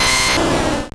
Cri de Florizarre dans Pokémon Or et Argent.